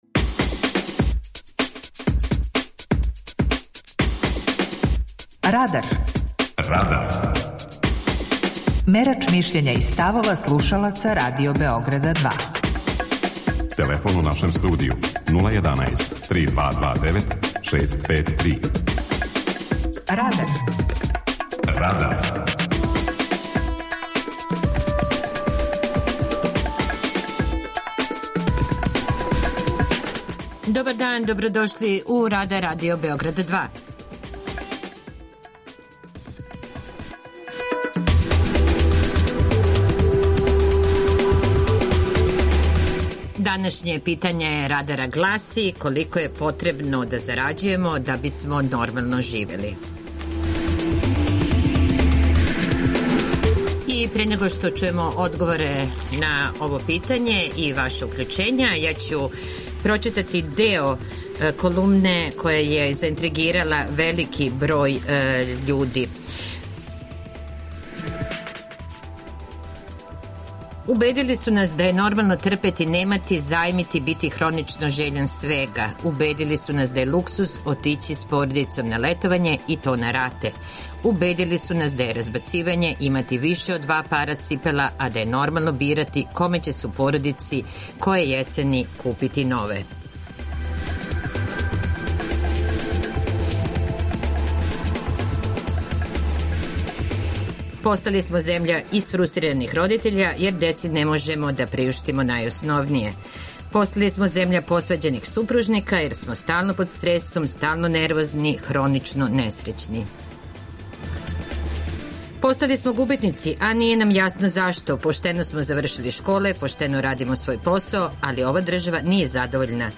Данашње питање за наше слушаоце је: колико треба зарађивати да би се нормално живело? преузми : 18.63 MB Радар Autor: Група аутора У емисији „Радар", гости и слушаоци разговарају о актуелним темама из друштвеног и културног живота.